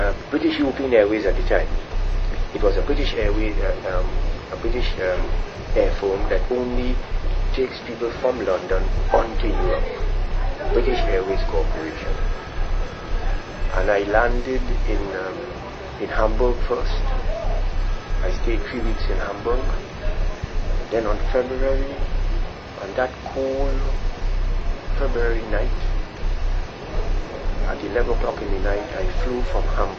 2 audio casscttes
The Oral and Pictorial Records Programme (OPReP)